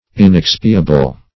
Inexpiable \In*ex"pi*a*ble\, a. [L. inexpiabilis: cf. F.